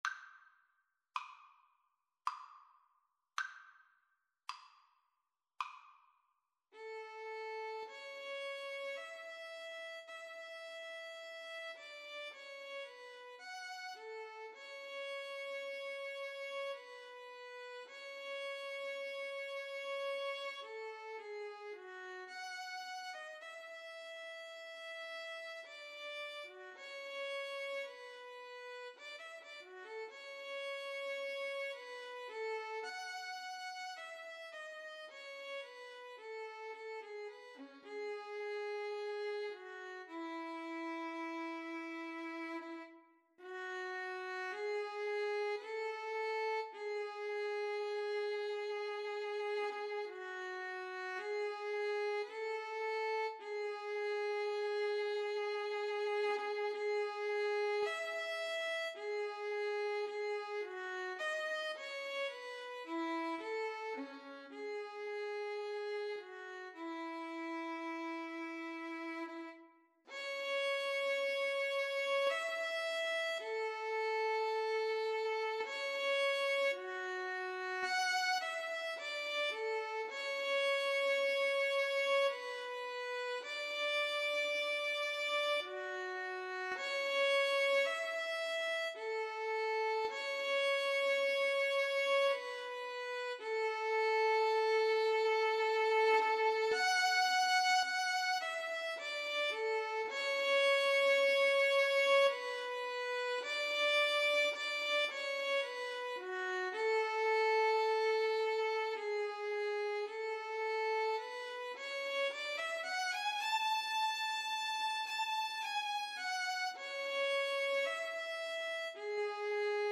3/4 (View more 3/4 Music)
Andante sostenuto ( = 54)
Classical (View more Classical Violin-Cello Duet Music)